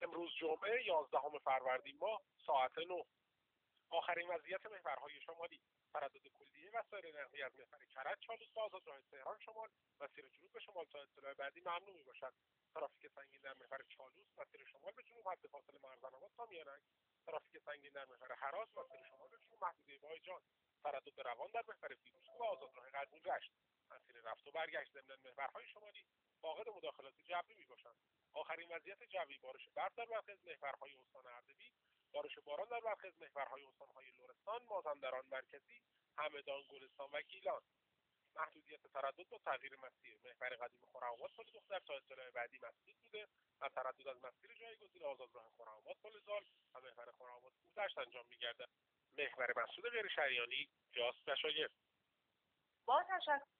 گزارش رادیو اینترنتی از آخرین وضعیت ترافیکی جاده‌ها تا ساعت ۹ یازدهم فروردین ماه؛